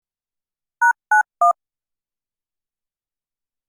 該当の項目をクリックすると、電話のプッシュ音のような音が流れます。